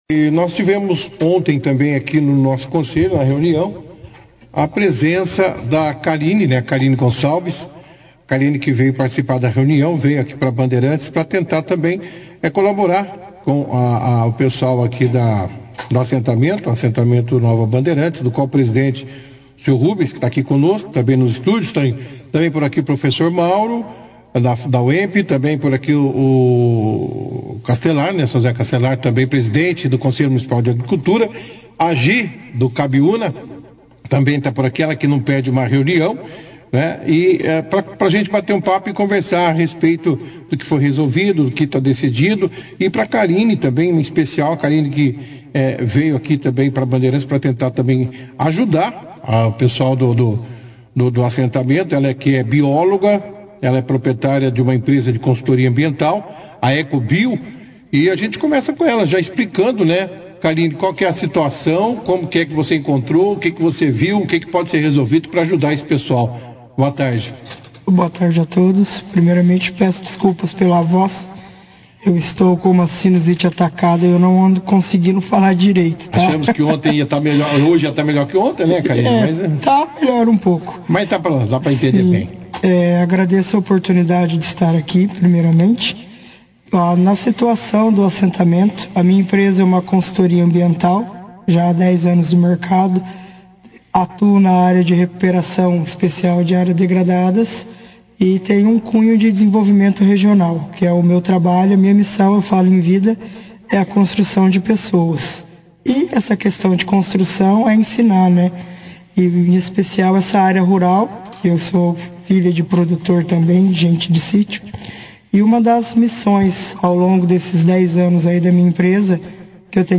detalharam as próximas etapas desse projeto durante a 2ª edição do jornal Operação Cidade, nesta quinta-feira, 14.